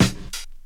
• 2000s Rap Snare Drum Sample F# Key 02.wav
Royality free acoustic snare sound tuned to the F# note. Loudest frequency: 1511Hz